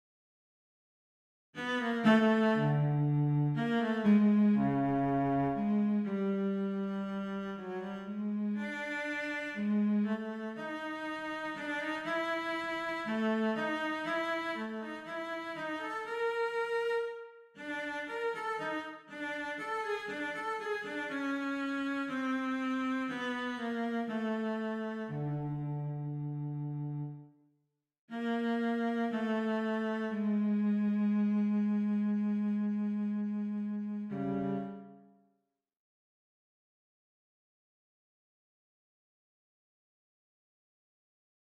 Voicing: Cello